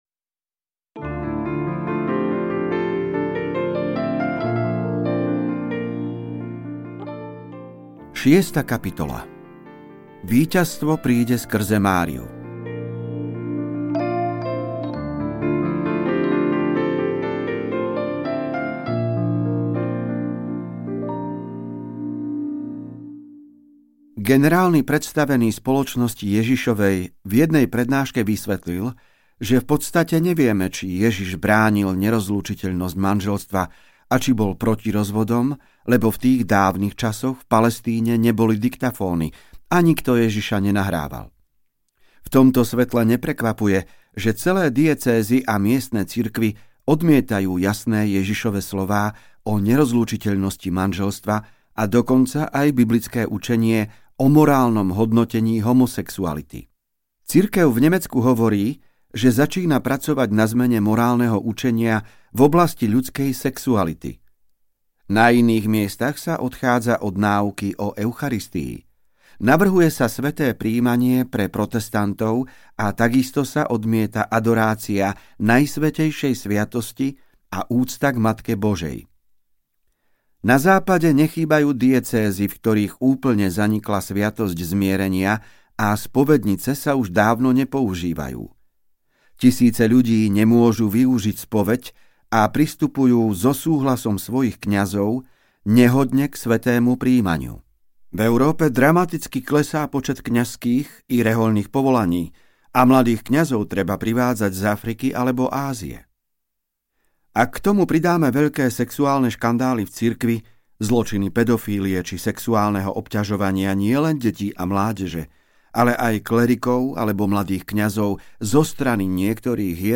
Posledné Máriino volanie audiokniha
Ukázka z knihy